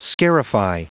Prononciation du mot scarify en anglais (fichier audio)
Prononciation du mot : scarify